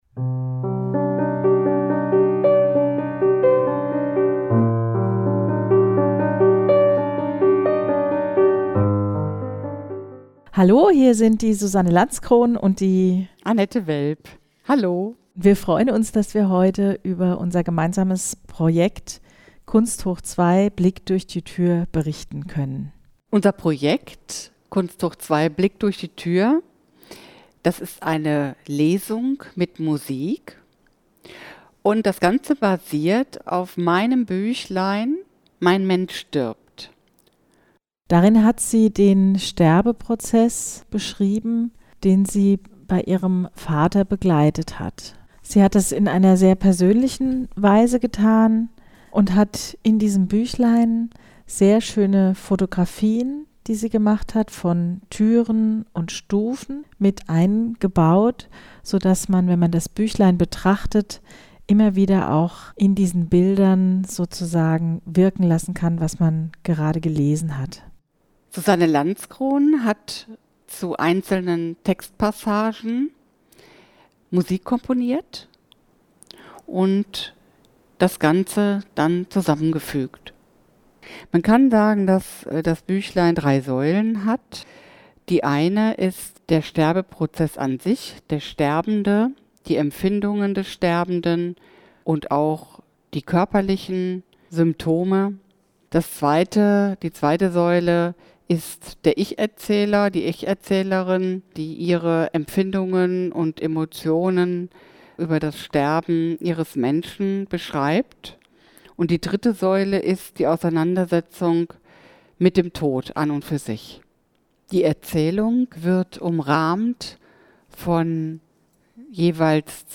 Unser kompletter Bericht, ungekürzt und mit der (GEMAfreien) Musik, kann hier angehört werden.